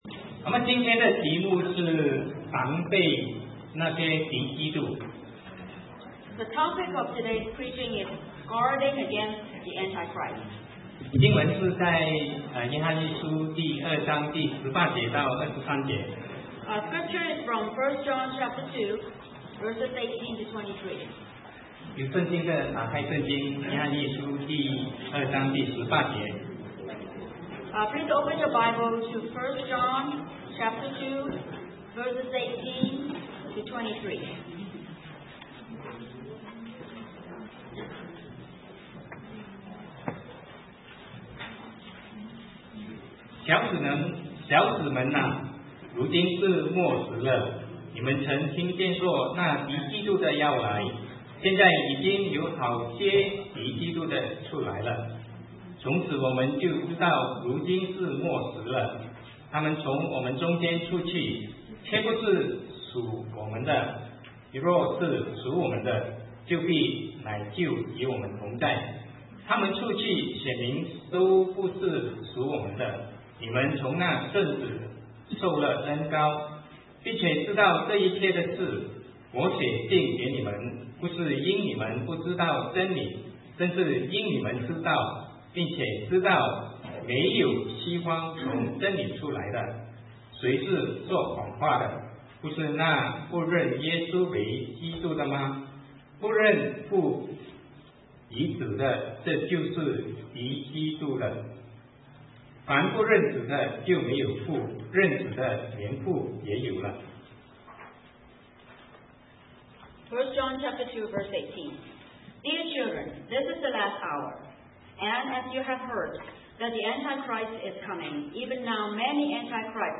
Sermon 2009-09-27 Guarding Against the Antichrists